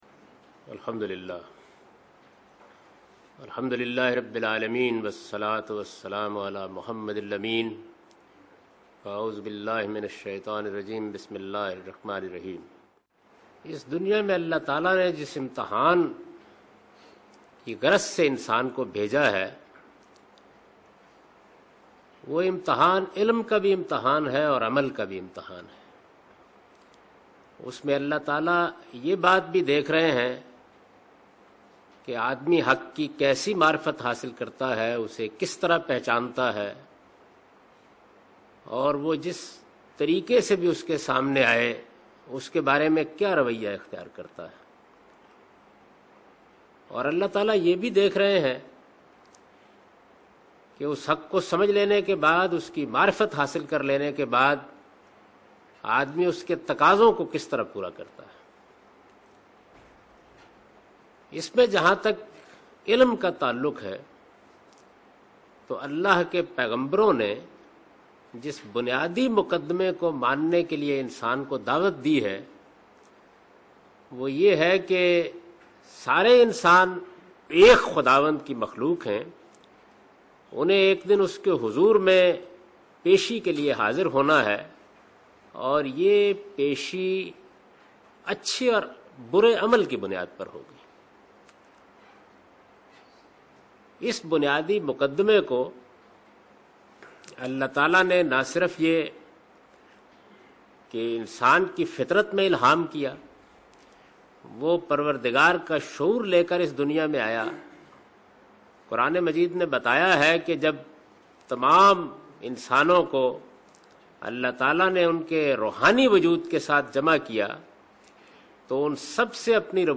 A comprehensive course on Quran, wherein Javed Ahmad Ghamidi teaches his tafseer ‘Al Bayan’. The book presents his understanding of Quran, resulting from a lifetime’s research and deliberations on the Quran. This lecture contains an introduction to his tafseer and basic principles of understanding Quran in Maktab Farahi.